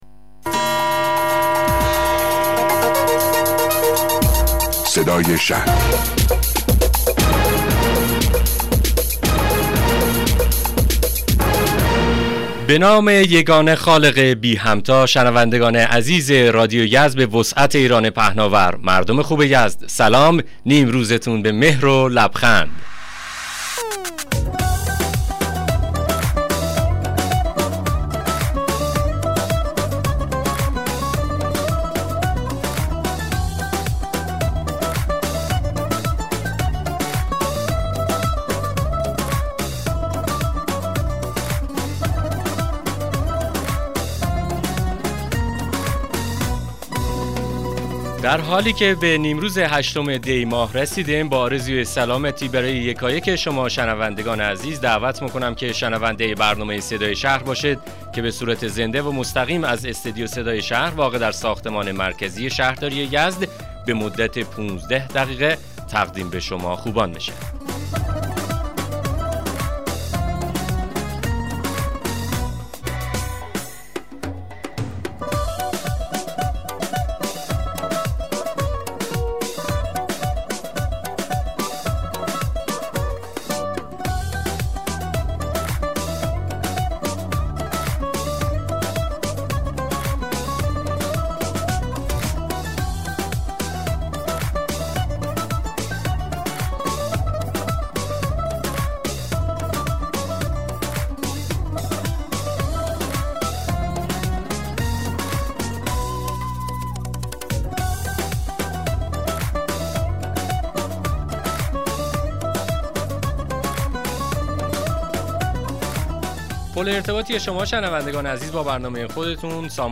مصاحبه رادیویی برنامه صدای شهر با حضور بی بی فاطمه حقیر السادات رییس کمیسیون شهر هوشمند شورای اسلامی شهر یزد